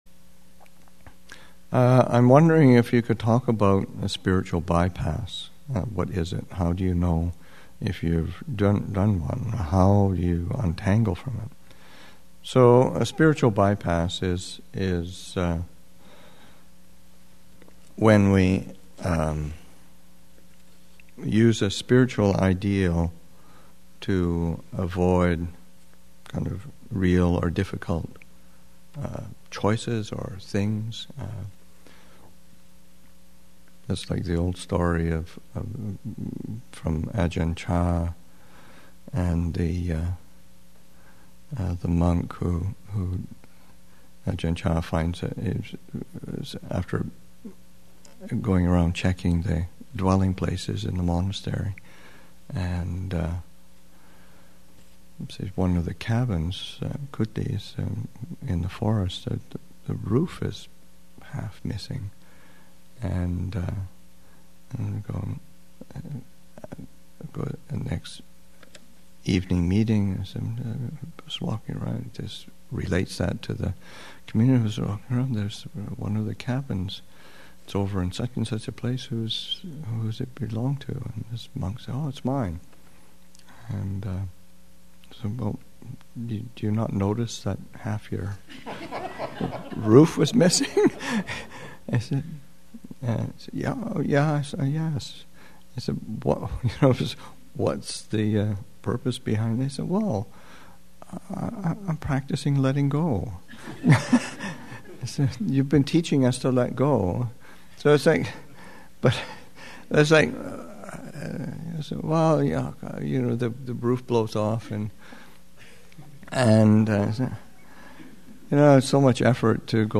Thanksgiving Retreat 2016, Session 3 – Nov. 21, 2016